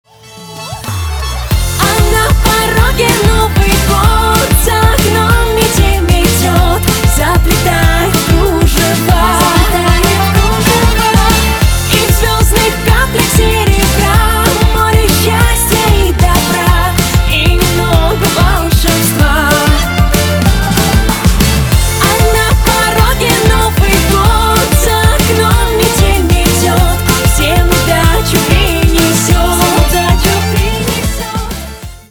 • Качество: 320, Stereo
поп
веселые
добрые